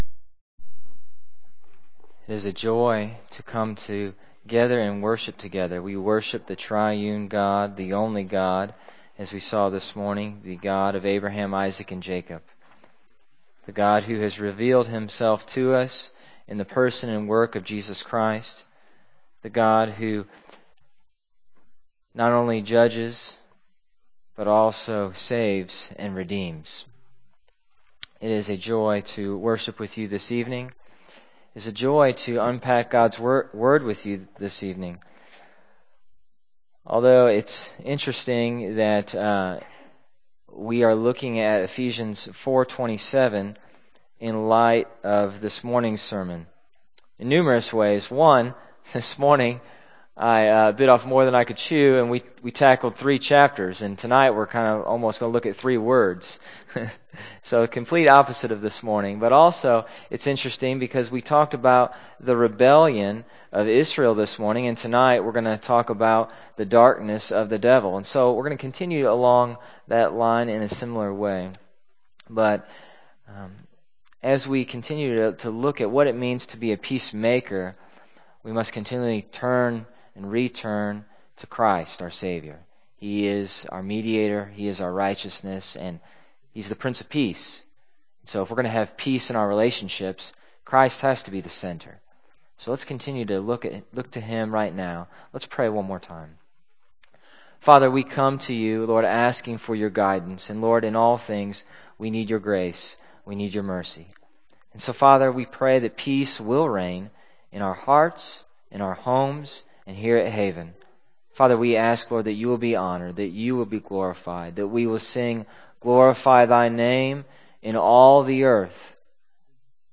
Evening Worship from October 5, 2014